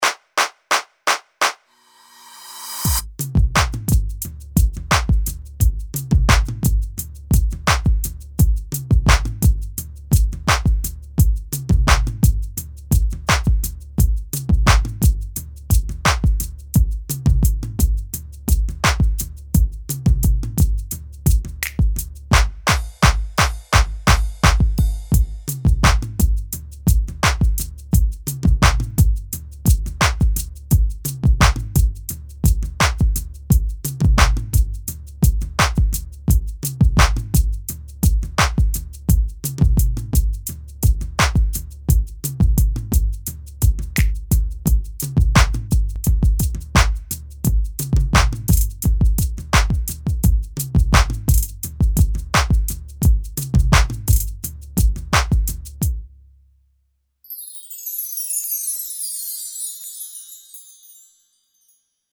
Audio Track without Vocals